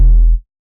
BASSUNO.wav